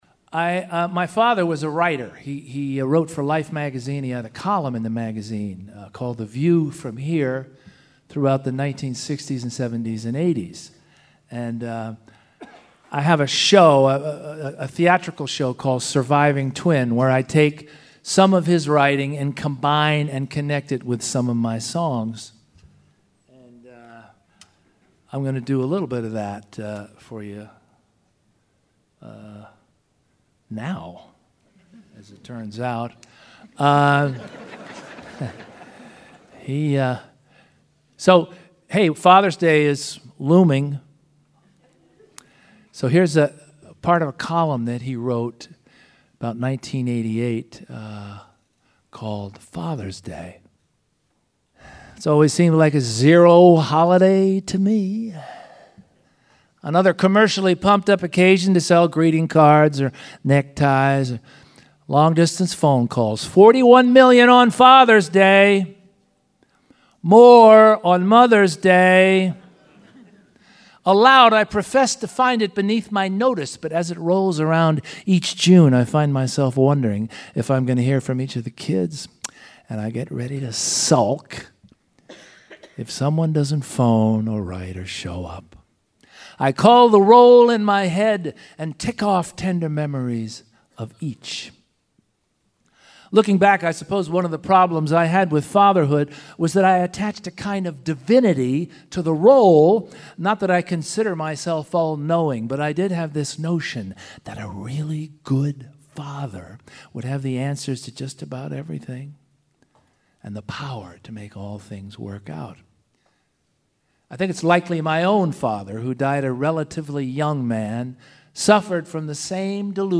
Culture Center Theater in Charleston, WV on May 7